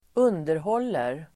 Uttal: [²'un:derhål:er]